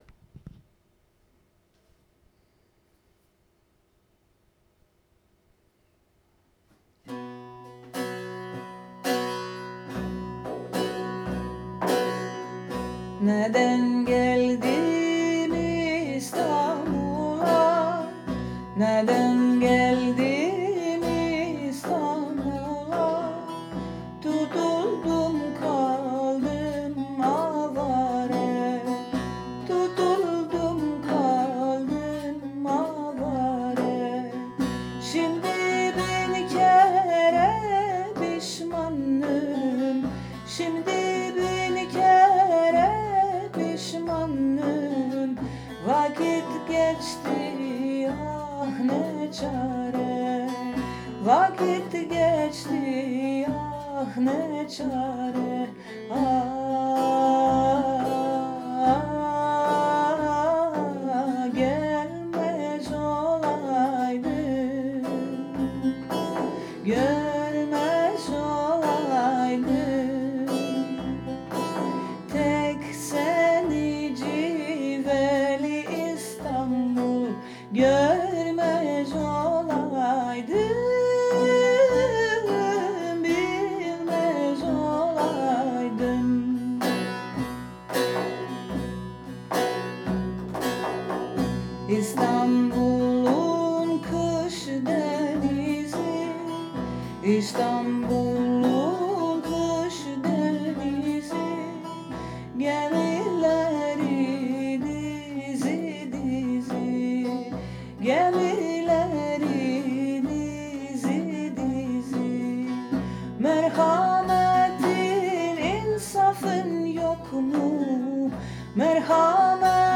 voice
saz